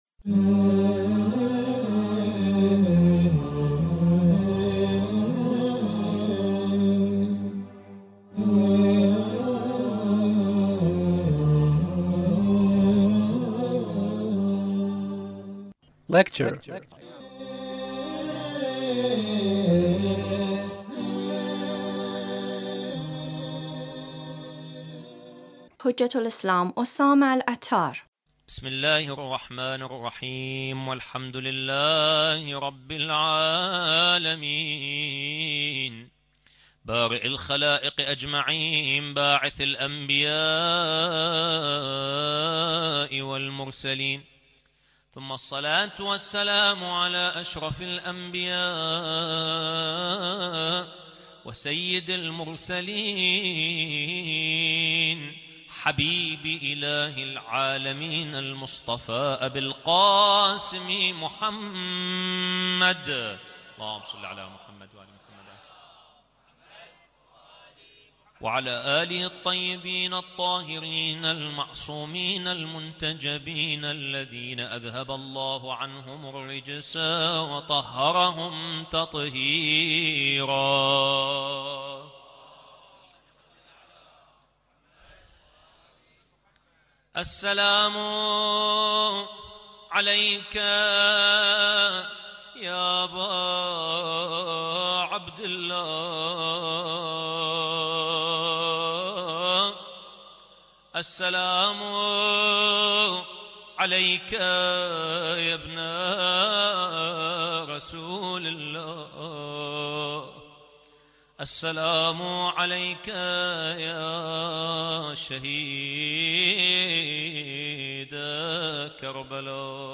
Lecture (22)